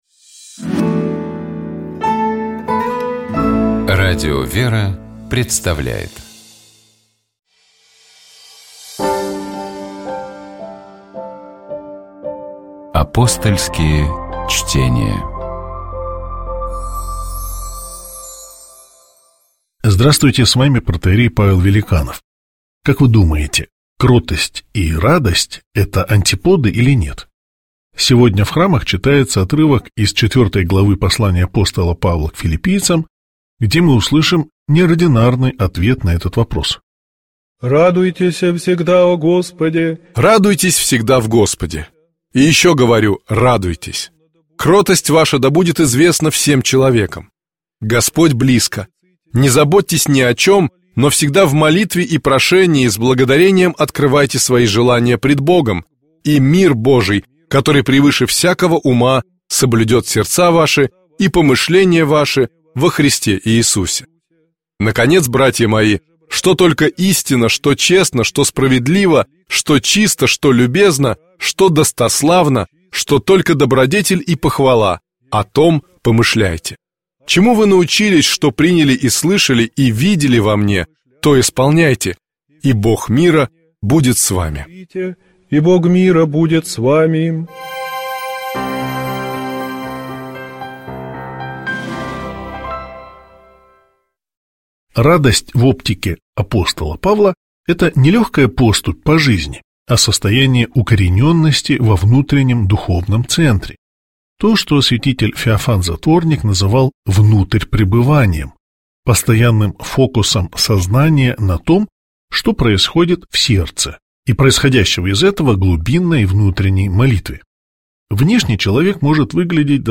Апостольские чтения